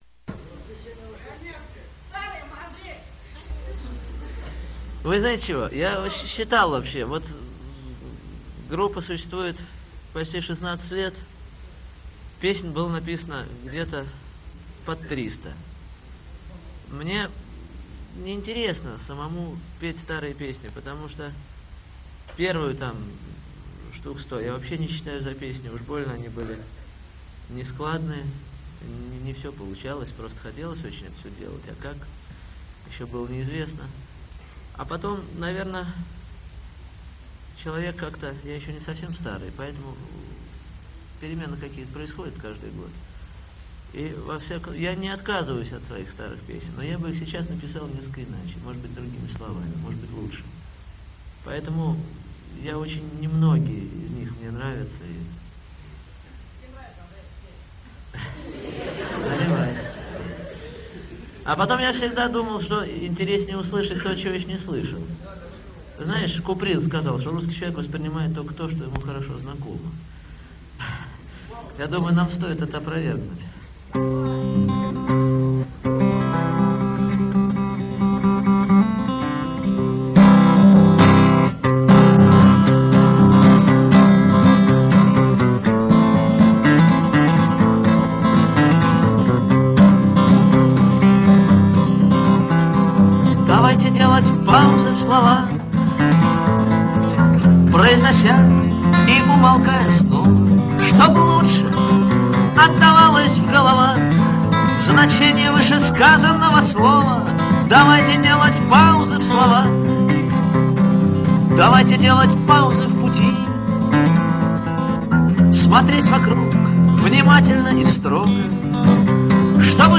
Сольный концерт
в московской школе